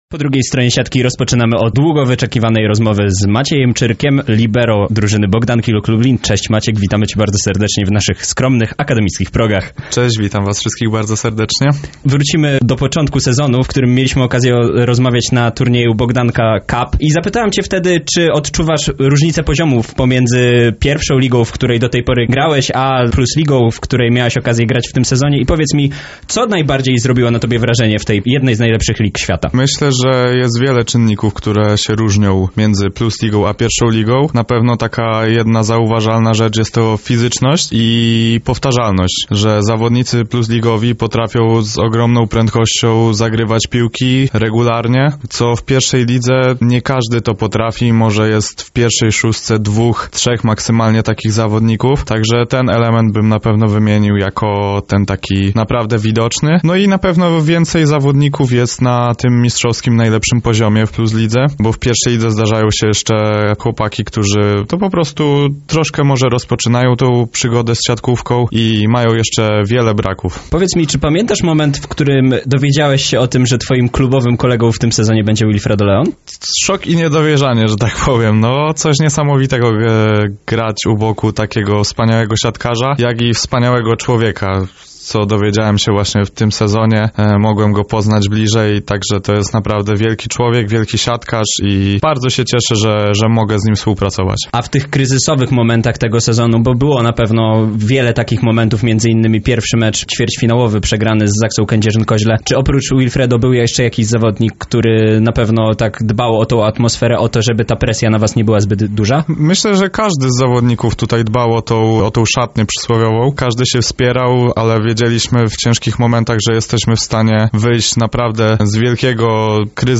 Rozmowa została zrealizowana w ramach specjalnego wydania programu ,,Po drugiej stronie siatki’’, który miał miejsce 19 maja w zastępstwie audycji ,,Arena Akademicka’’.